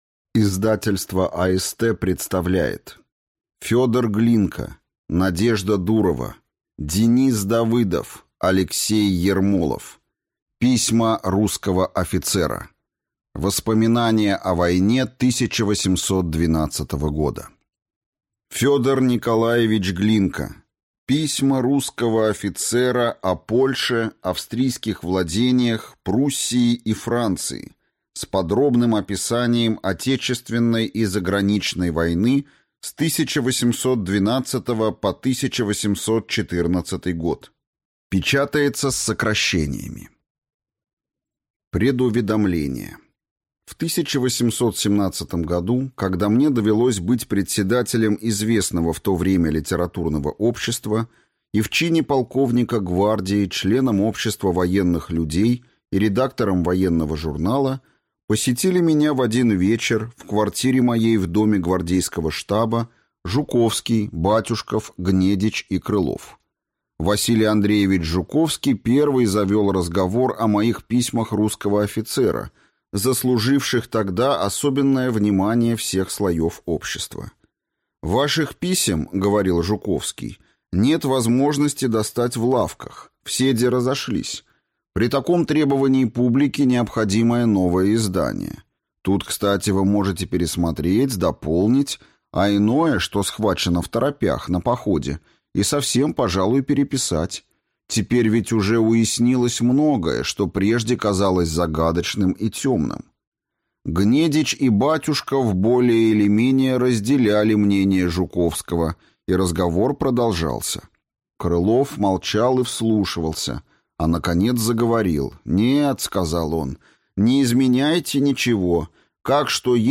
Аудиокнига Письма русского офицера. Воспоминания о войне 1812 года | Библиотека аудиокниг